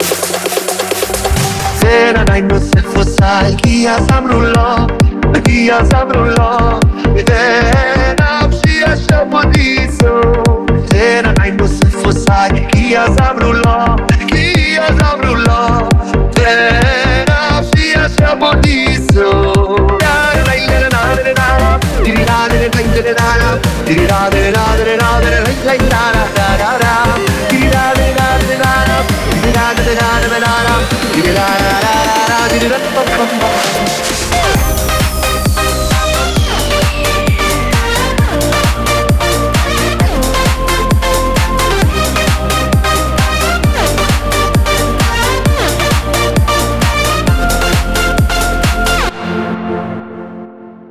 FutureBass Classic.wav FutureBass Classic.mp3